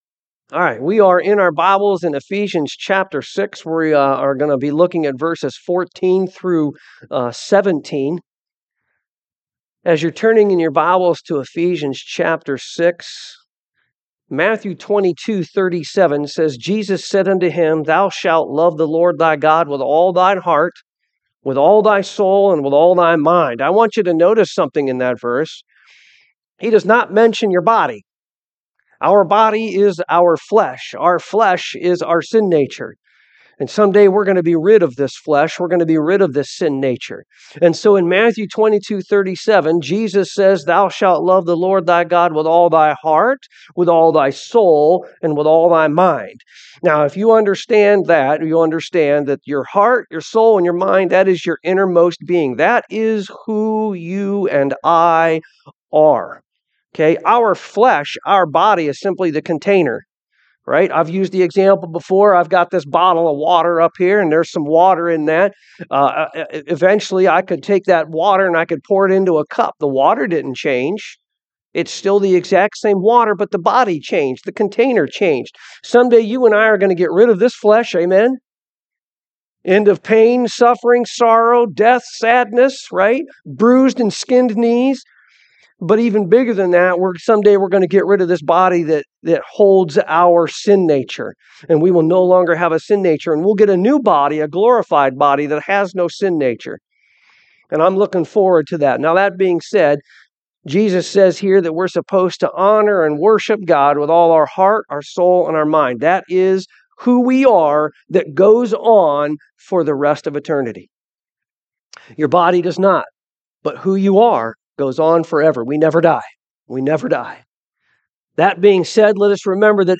Ephesians 6:14-17 Service Type: AM God calls us to lay down distractions and pick up the armor of God.